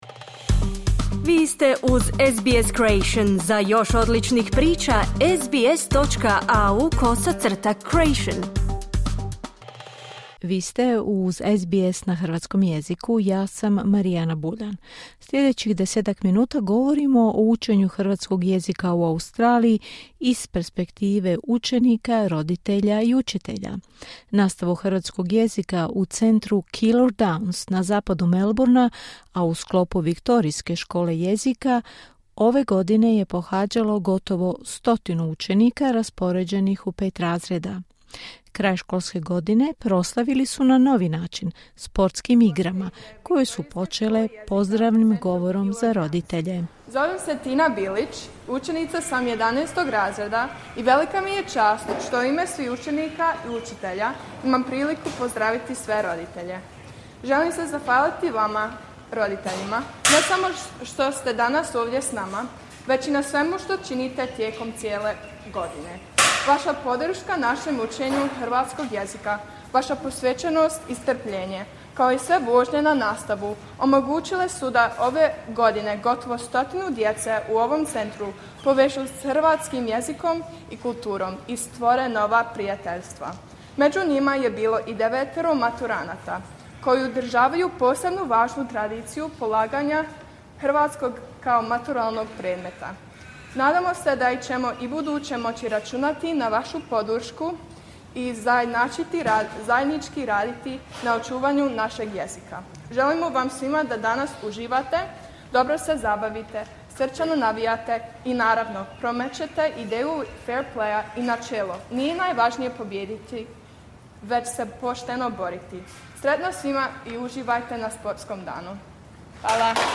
Kraj školske godine proslavili su na novi način, prvim sportskim igrama u kojima su sudjelovali učenici uz pomoć roditelja i učitelja. Prenosimo vam atmosferu i razgovore o važnostima učenja jezika, ulozi roditelja i zaslugama učitelja.